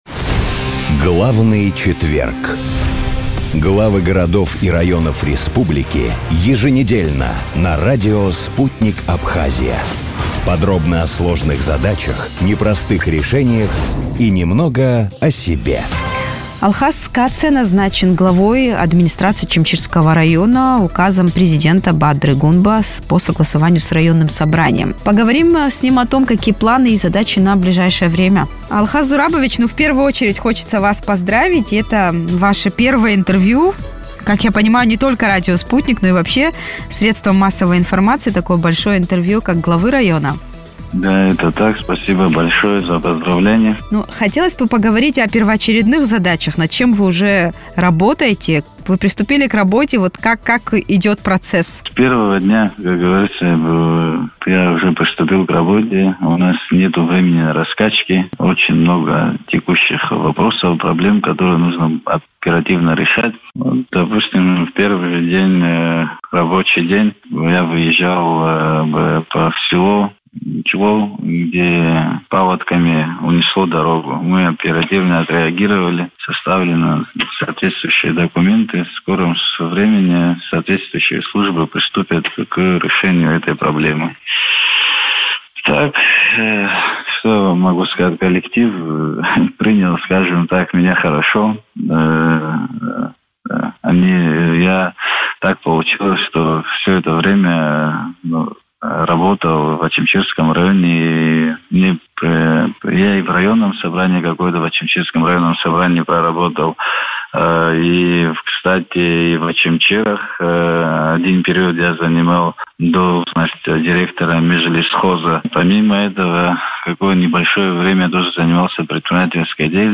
О планах и задачах на ближайшее время он рассказал в интервью радио Sputnik.